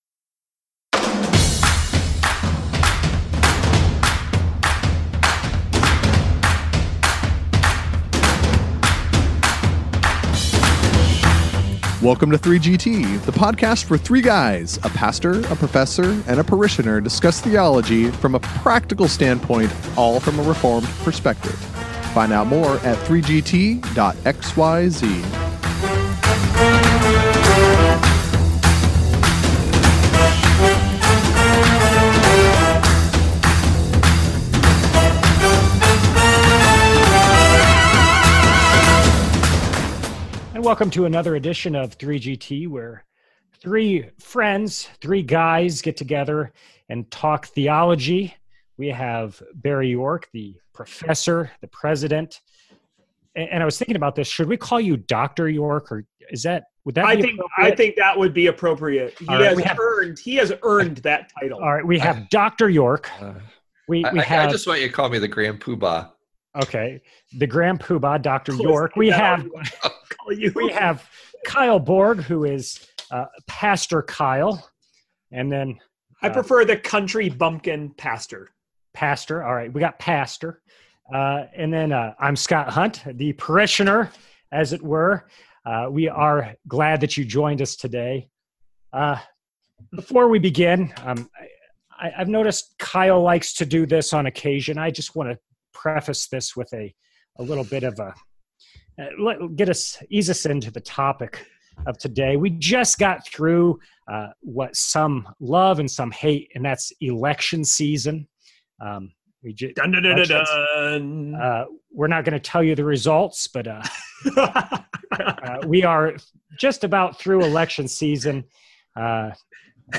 The guys have a vigorous discussion about the level of adherance one should have to the confession of faith of his or her church.